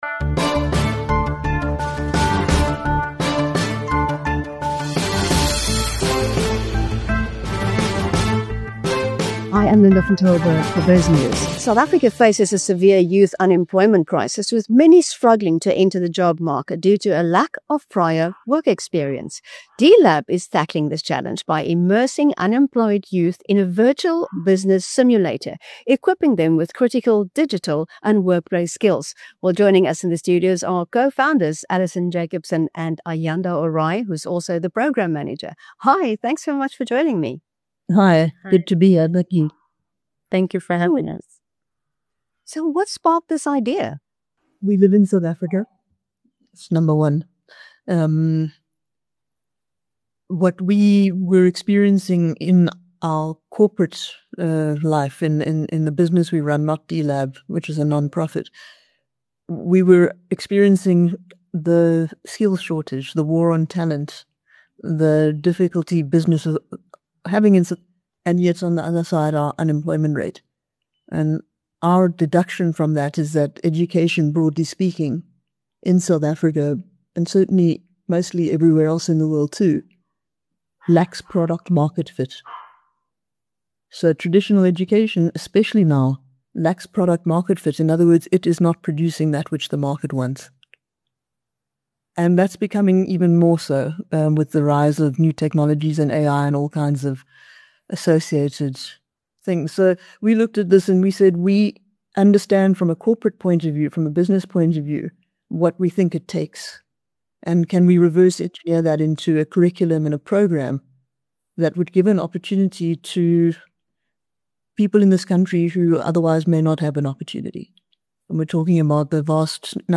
South Africa’s youth unemployment crisis leaves many young people struggling to secure jobs due to limited experience. D-Lab is tackling this challenge by immersing unemployed youth in a virtual business simulator, equipping them with essential digital and workplace skills. In an interview with Biznews